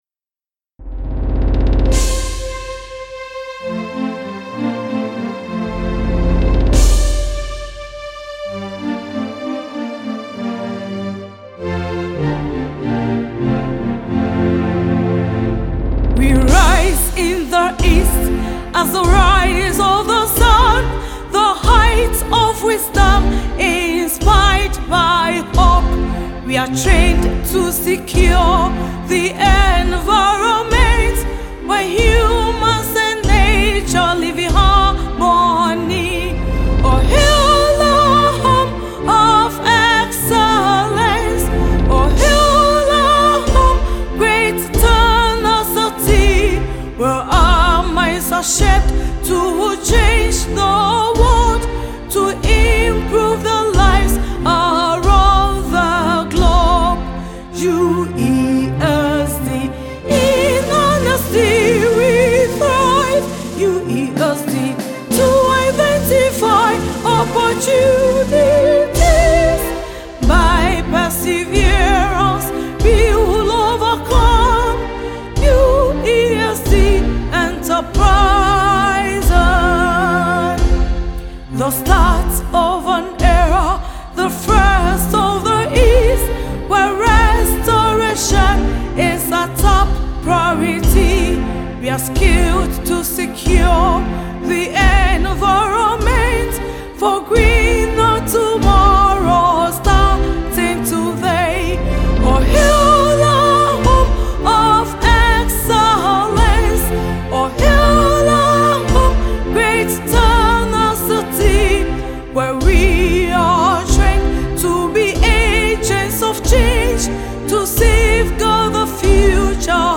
The anthem is a rousing and optimistic piece that combines elements of classical music, folk influences, and contemporary rhythms. The lyrics celebrate the University’s dedication to environmental stewardship, social responsibility, and economic viability.